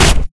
Blow2.ogg